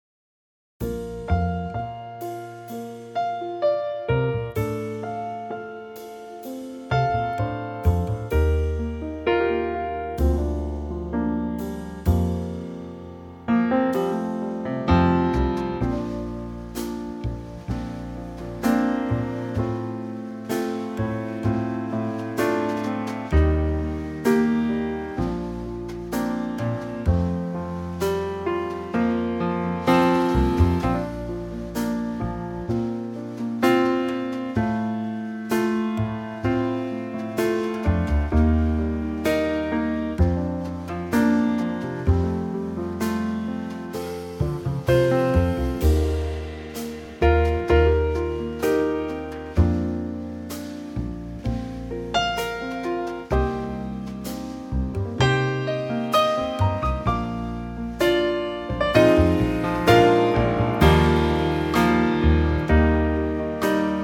Unique Backing Tracks
4 bar intro and vocal in at 15 seconds. vocal through
key - Bb - vocal range - A to Eb
in a very lovely Trio arrangement.